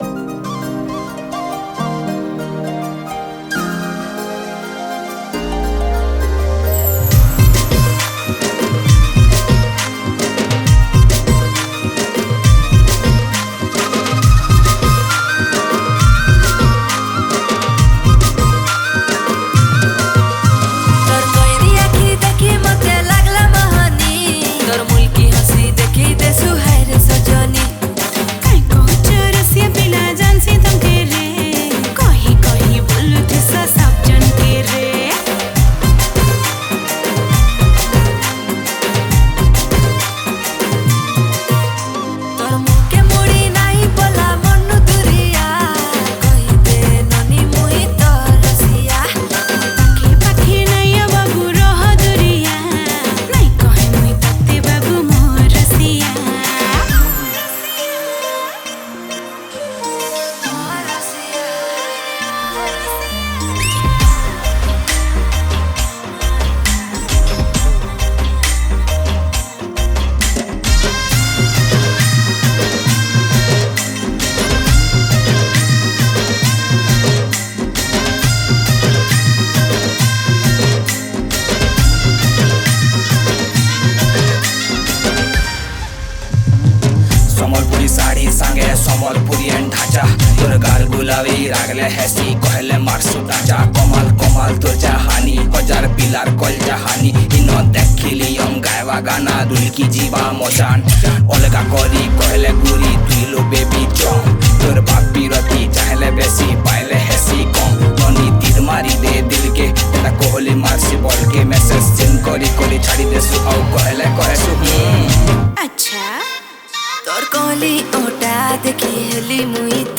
Category: New Sambalpuri Songs 2022